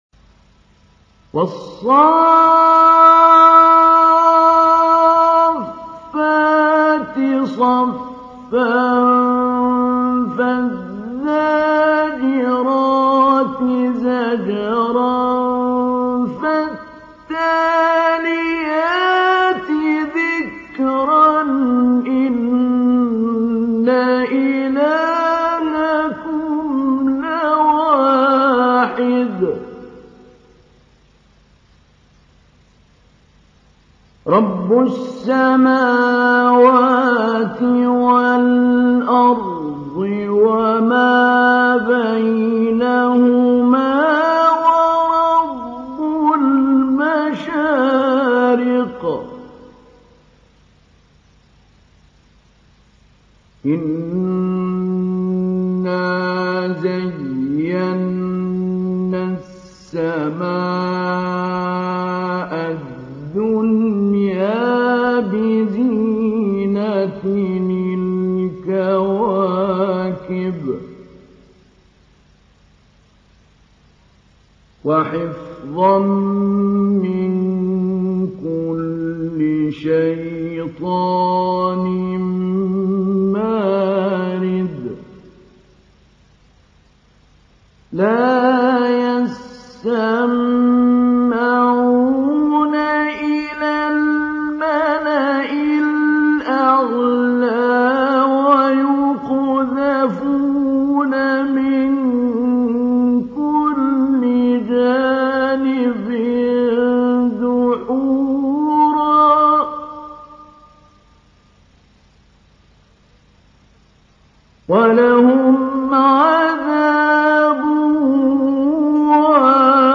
تحميل : 37. سورة الصافات / القارئ محمود علي البنا / القرآن الكريم / موقع يا حسين